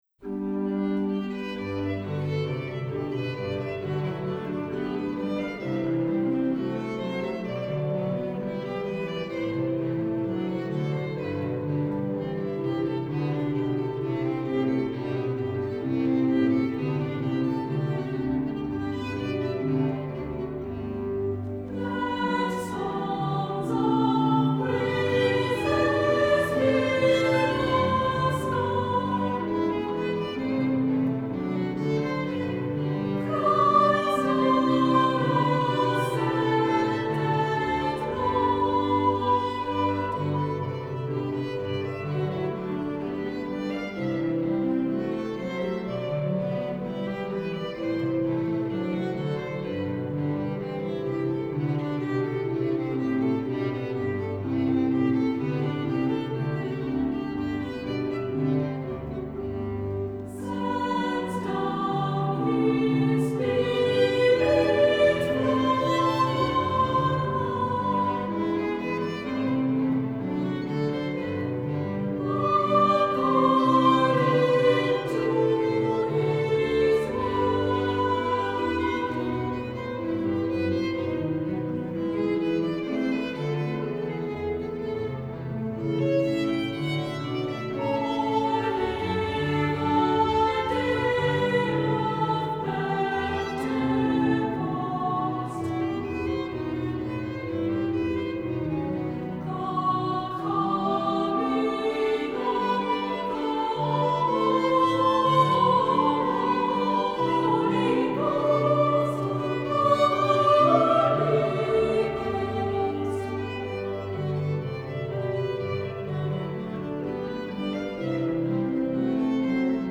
Accompaniment:      Organ
Music Category:      Early Music
Unison or SATB. Violin, Cello and Continuo.